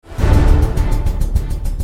SFX惊悚悬疑震撼出场音效下载
SFX音效